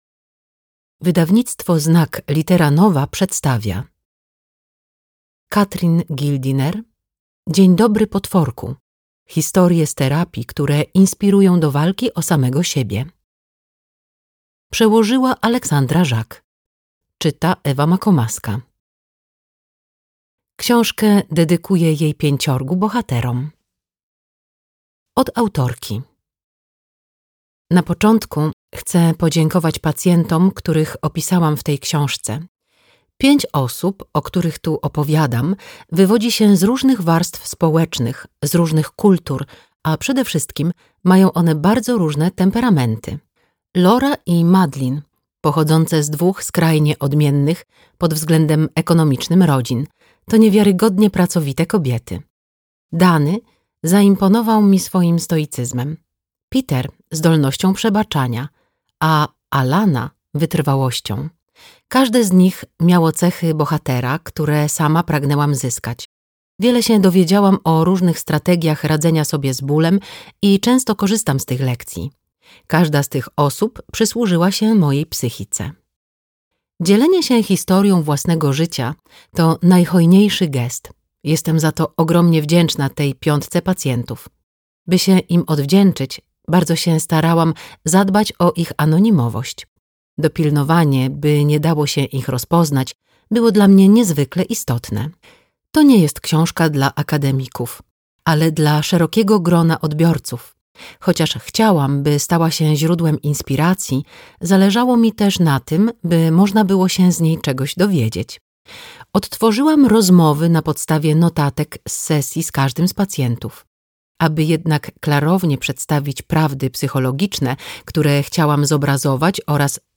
Dzień dobry, potworku. Historie z terapii, które inspirują do walki o samego siebie - Gildiner Catherine - audiobook